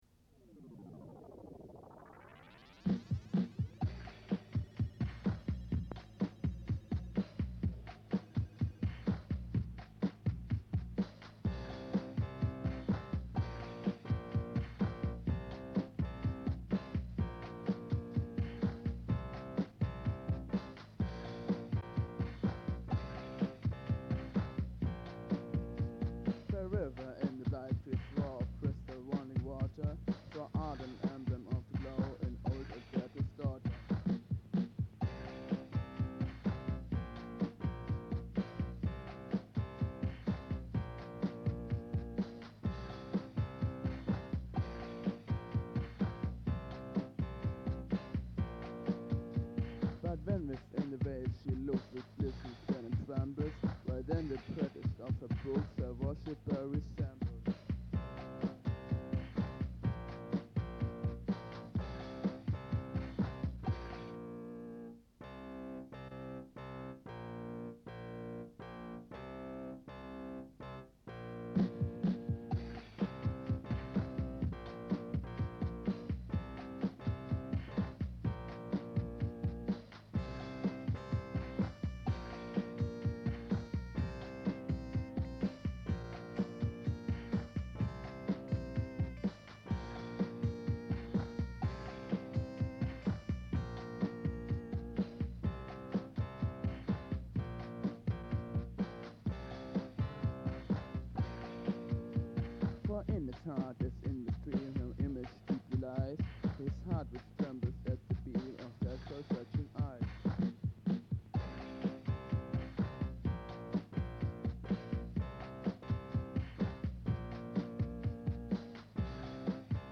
Im Esszimmer meiner Eltern sampelten wir den Drumloop von Funky Cold Medina, ich spielte dazu zwei Töne und wollte außerdem noch rappen.
Besonders auffällig die interessant kurzen Strophen, die wohl nur aus jeweils vier Zeilen bestehen, damit dürfte der komplette Text ungefähr ein 100stel des Textes von „Rapper’s Delight“ ausmachen.